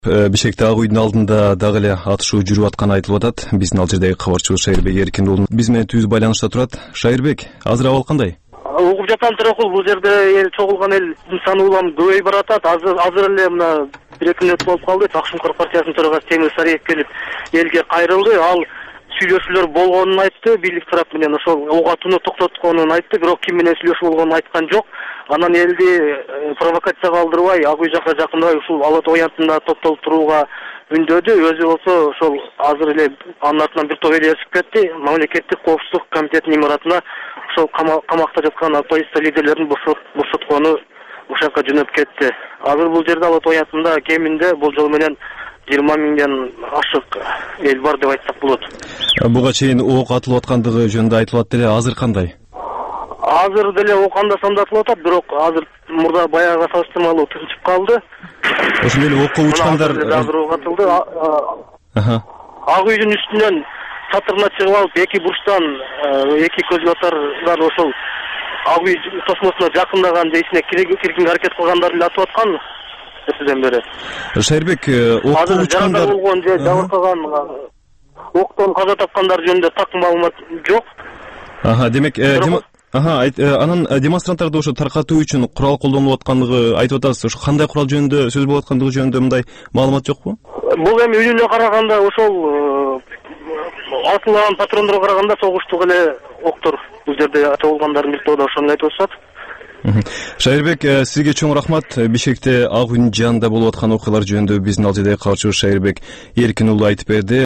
7-апрель, 2010 (түз эфир)